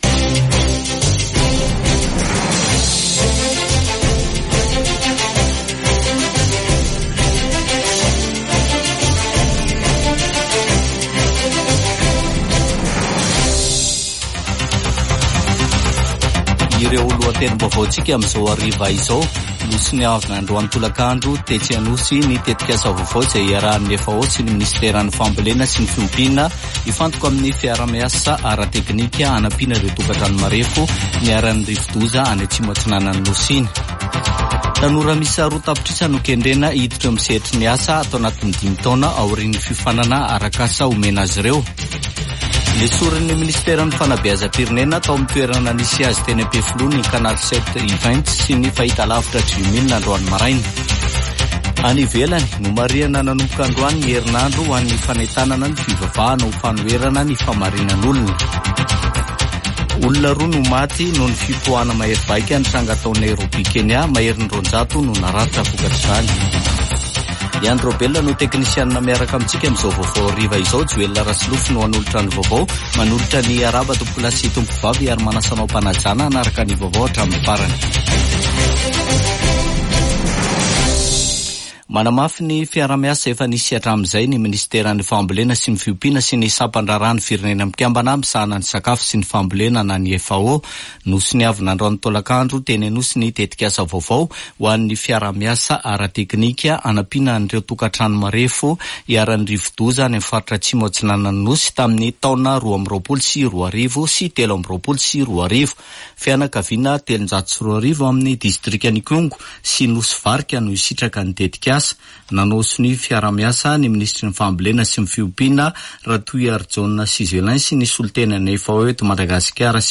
[Vaovao hariva] Zoma 2 febroary 2024